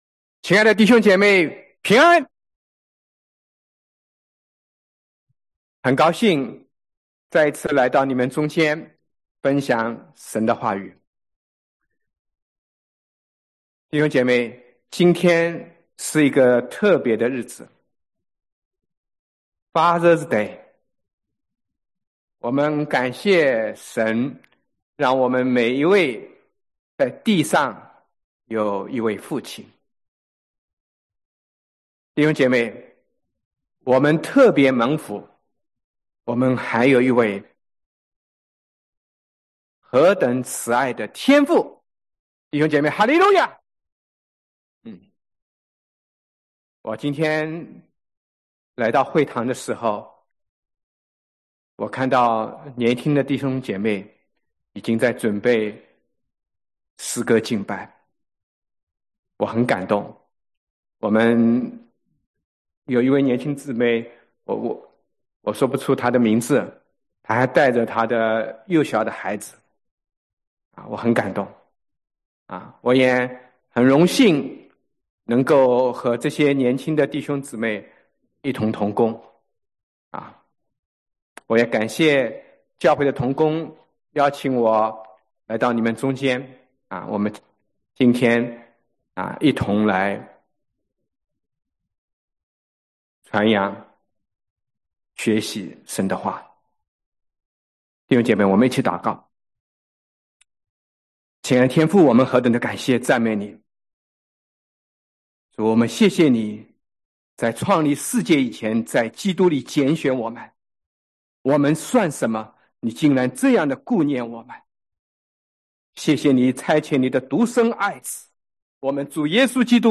证道：人的邪恶，神的恩典 Evil of man, Grace of God